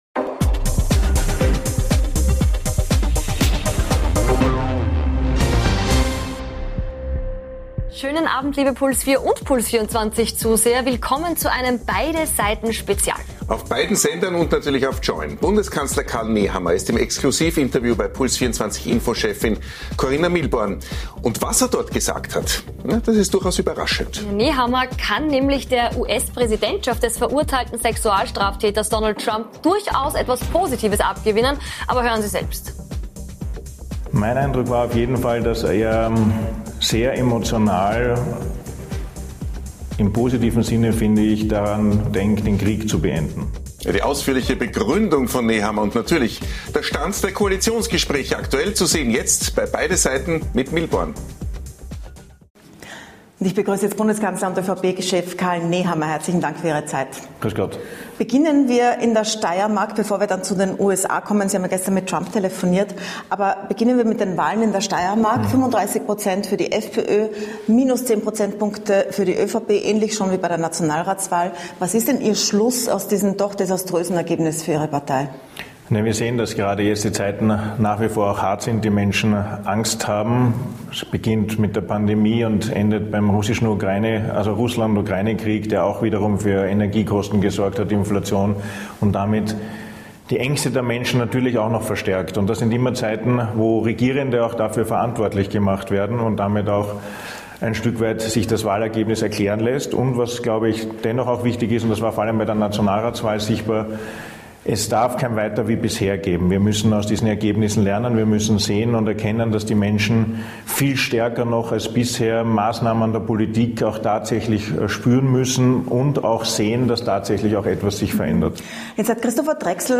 Spezial: Bundeskanzler Karl Nehammer im großen Interview ~ Beide Seiten Live Podcast